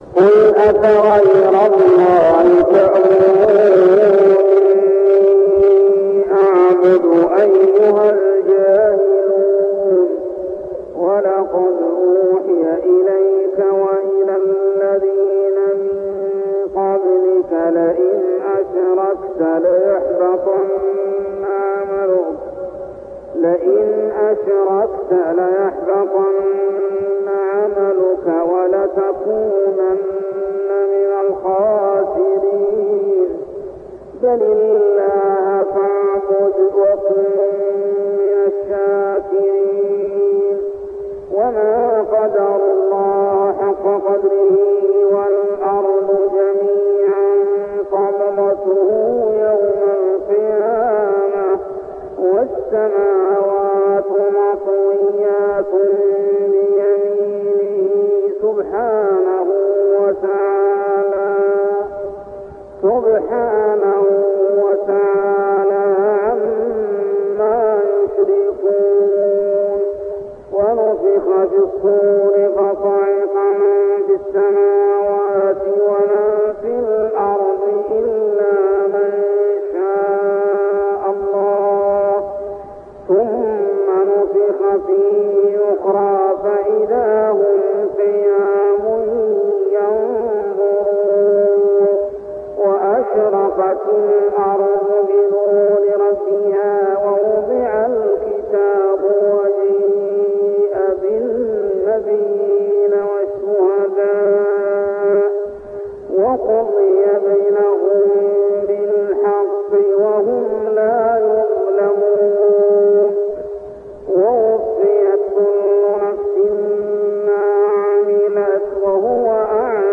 تلاوة من صلاة الفجر لخواتيم سورة الزمر 64-75 عام 1399هـ | Fajr prayer Surah Az-Zumar > 1399 🕋 > الفروض - تلاوات الحرمين